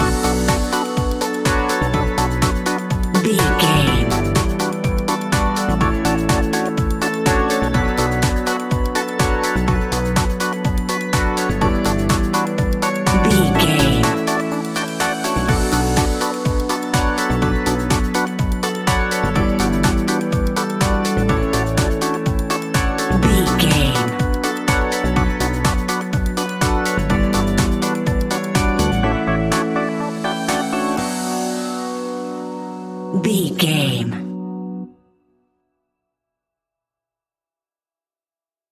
Aeolian/Minor
groovy
hypnotic
uplifting
synthesiser
drum machine
electric guitar
funky house
deep house
nu disco
upbeat
funky guitar
clavinet
synth bass
horns